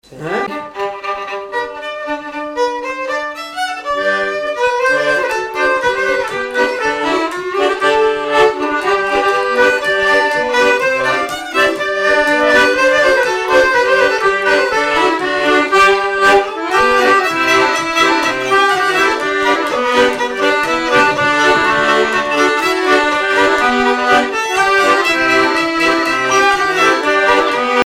danse : marche
violon
Pièce musicale inédite